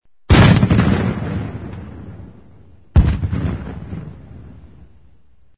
Descarga de Sonidos mp3 Gratis: disparo 9.
Disparodisparoeco.mp3